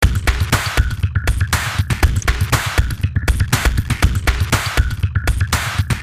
挥舞一条长鞭子
描述：挥舞一条长鞭子。只是抽打空气。
标签： 抽打 绑扎 痛打
声道立体声